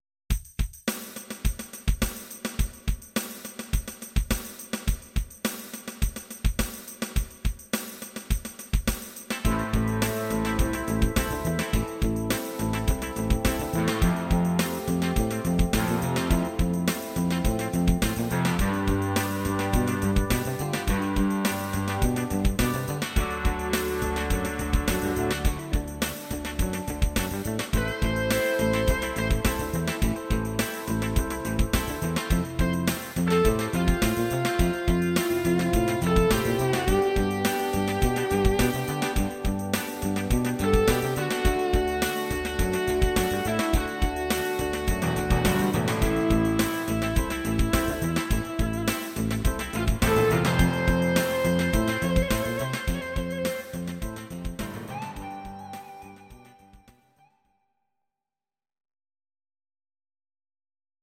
Audio Recordings based on Midi-files
Oldies, Instrumental, 1960s